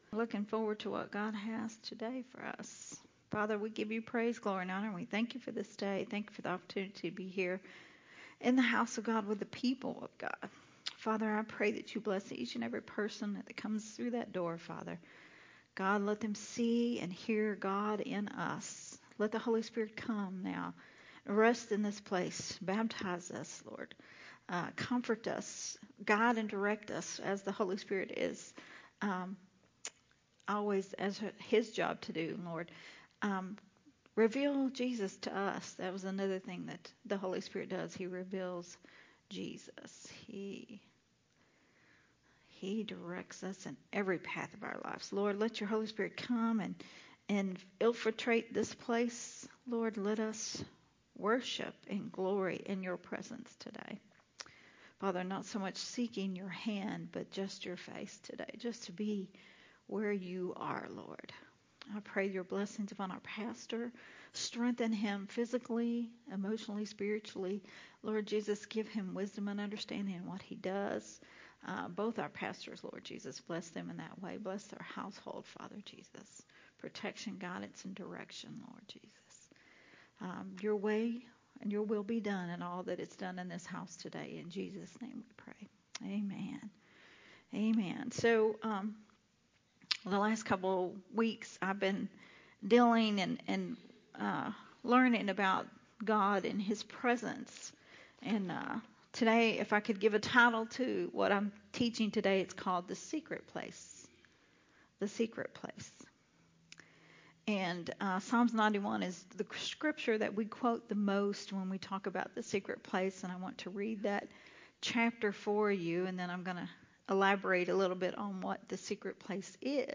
recorded at Unity Worship Center on April 23, 2023.